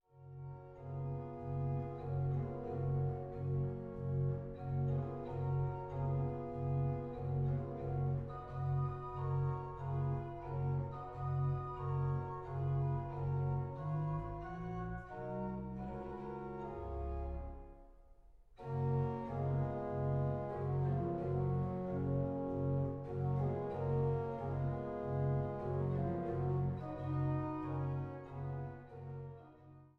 Barockoboe
Trost-Orgel in Großengottern